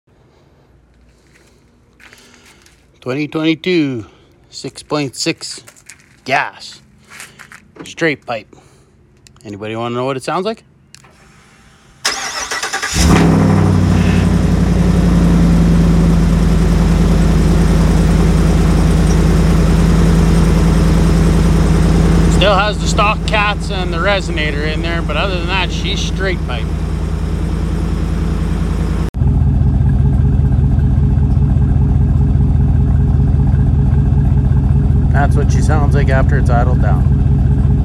Straight piped 6.6 gas after sound effects free download
Straight piped 6.6 gas after sitting overnight.